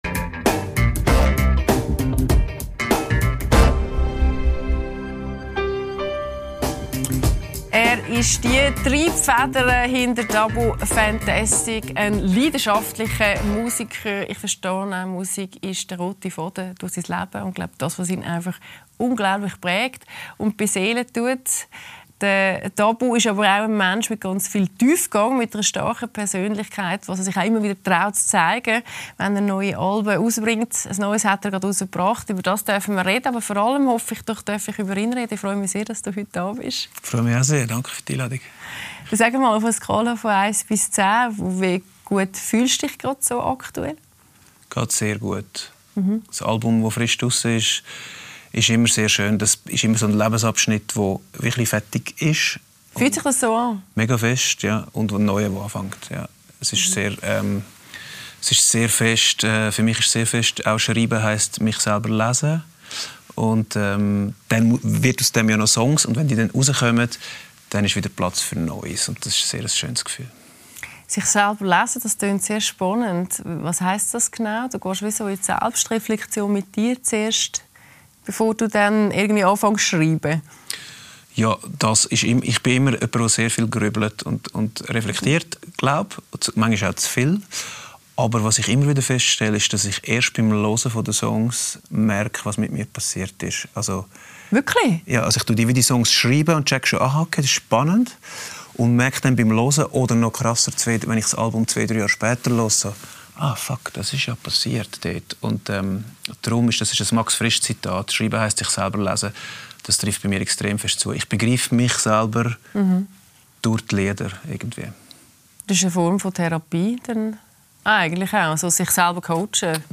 Mit Dabu Bucher ~ LÄSSER ⎥ Die Talkshow Podcast